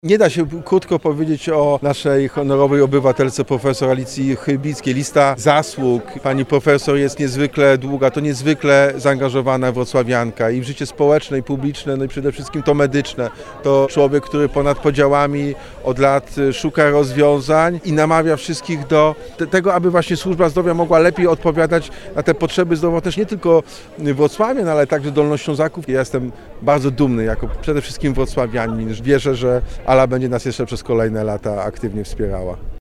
Wyróżnienie wręczył prezydent Wrocławia Jacek Sutryk. Podkreślił, iż decyzja radnych była jednomyślna.